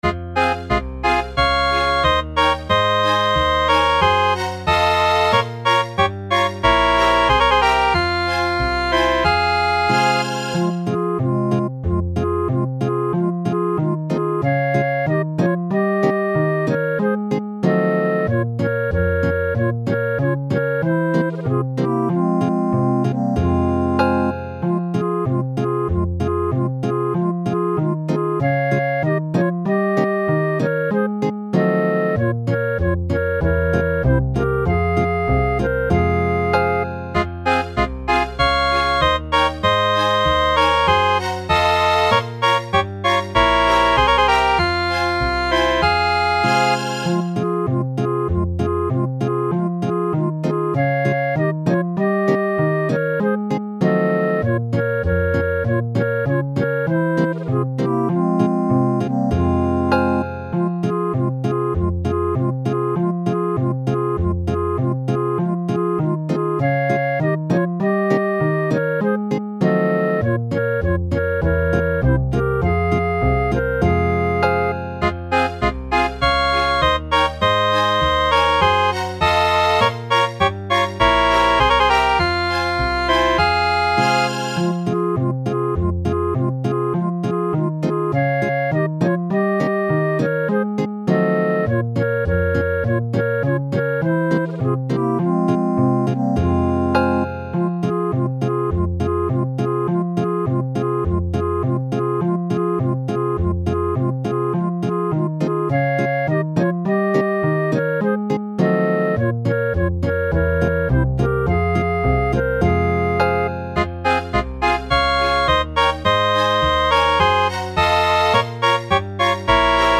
Фонограма-мінус (mp3)